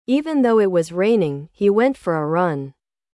1. Though (Embora) – pronúncia: /ðoʊ/ – tradução do som: “Dôu”